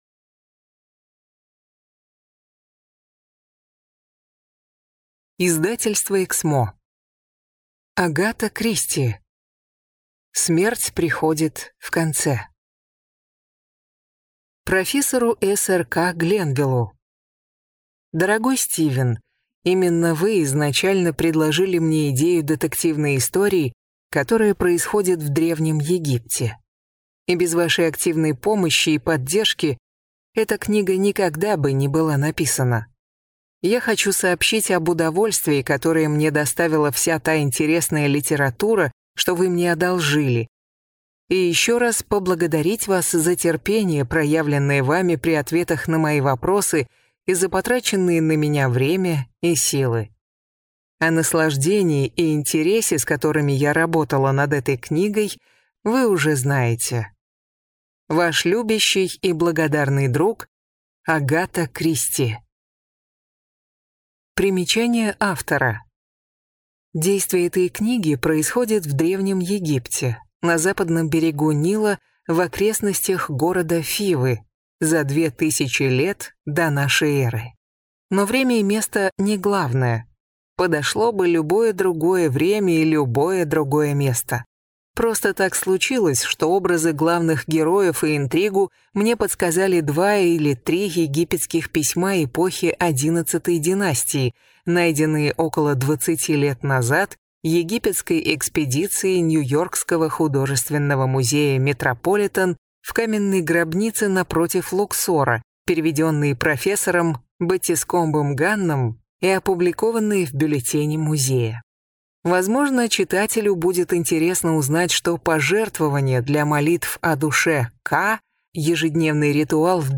Аудиокнига «Смерть приходит в конце» в интернет-магазине КнигоПоиск ✅ Зарубежная литература в аудиоформате ✅ Скачать Смерть приходит в конце в mp3 или слушать онлайн